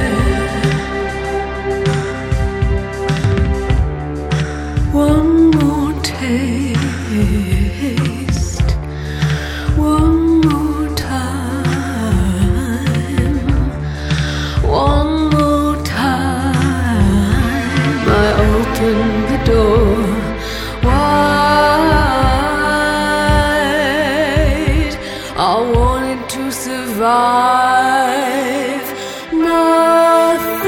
Musique audio
Rock et variétés internationales